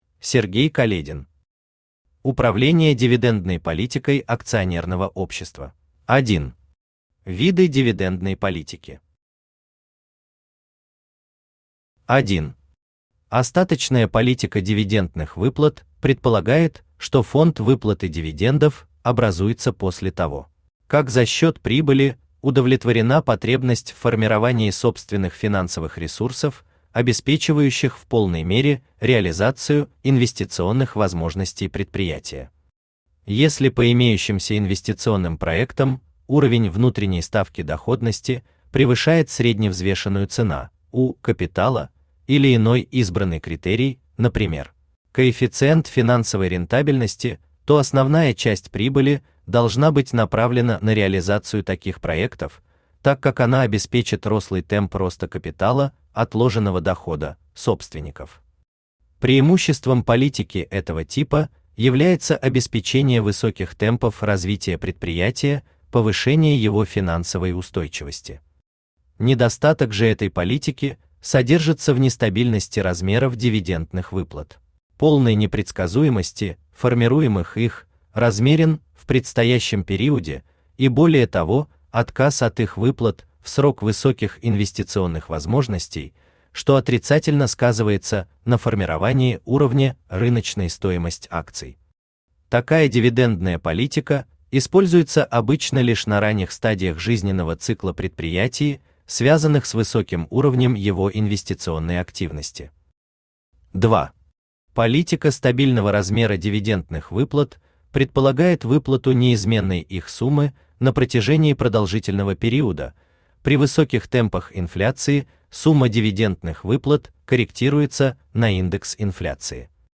Аудиокнига Управление дивидендной политикой акционерного общества | Библиотека аудиокниг
Aудиокнига Управление дивидендной политикой акционерного общества Автор Сергей Каледин Читает аудиокнигу Авточтец ЛитРес.